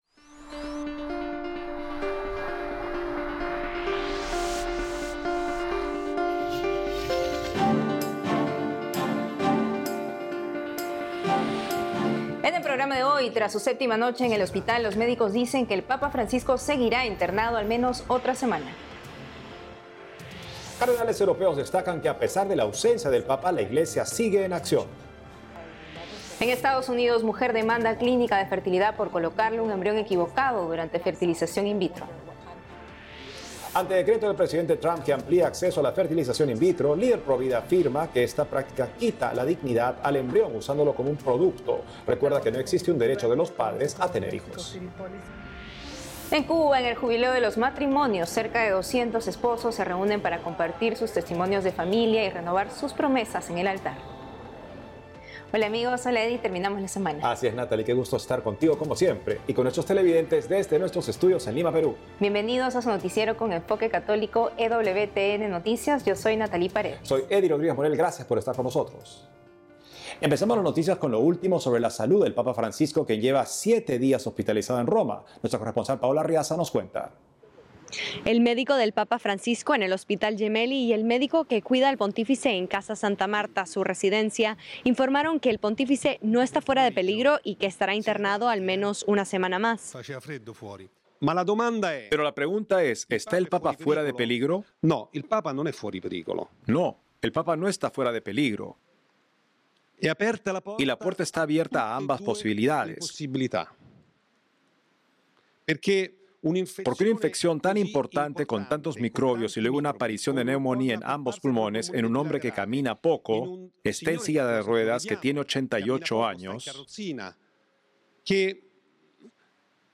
Noticiero diario producido exclusivamente para EWTN por la agencia ACI Prensa de Perú. Este programa informativo de media hora de duración se emite los sábados (con repeticiones durante la semana) y aborda noticias católicas del mundo y las actividades de Su Santidad Francisco; incluye también reportajes a destacados católicos de América del Sur y América Central.